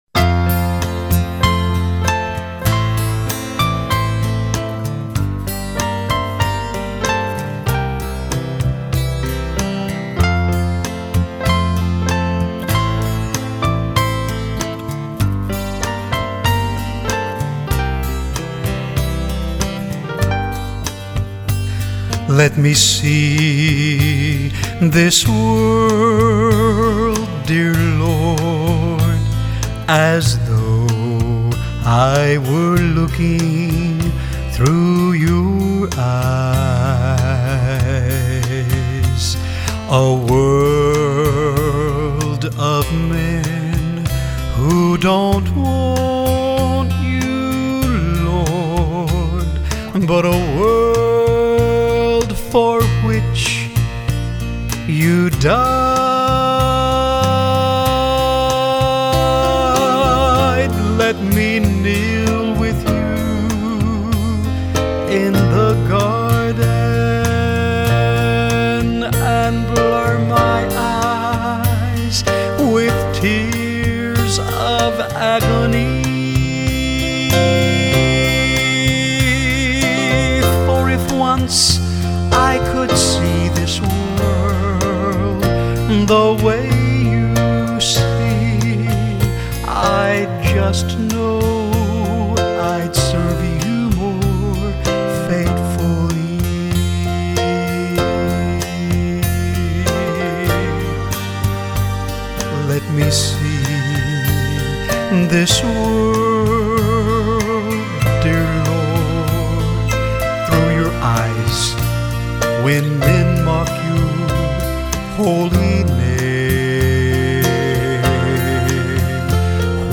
singing group